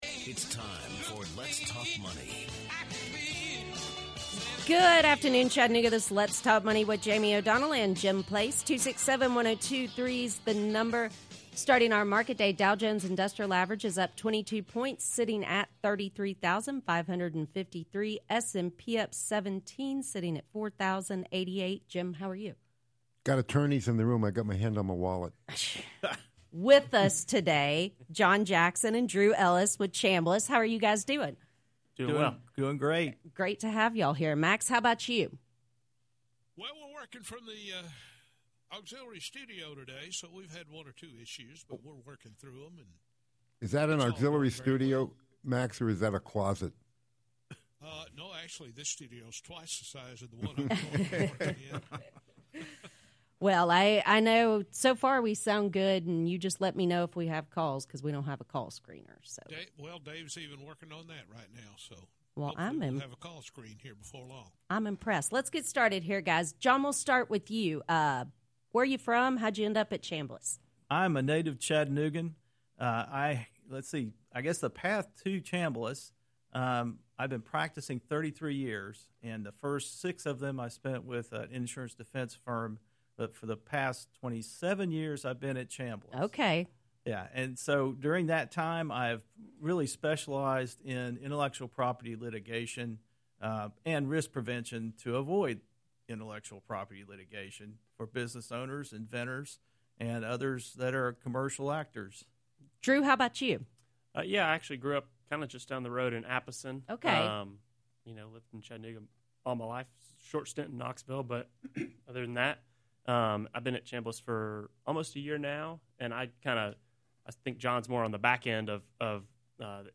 Let’s Talk Money is hosted by Evergreen Advisors weekdays from 12 to 1 p.m. on WGOW 102.3FM.